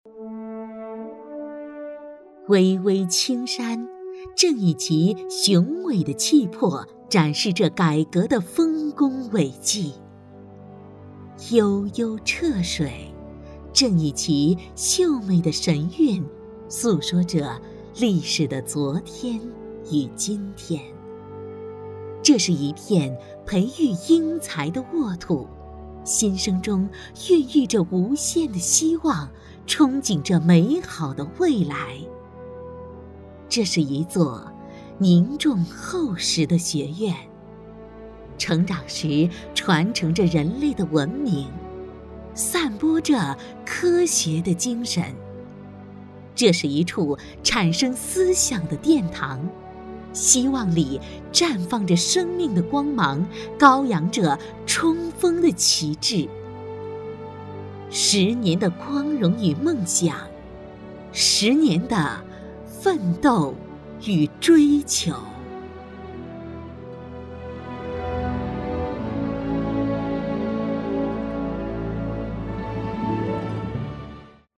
配音风格： 感情丰富，自然活力
【专题】培育英才的沃土